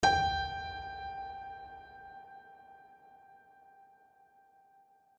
HardPiano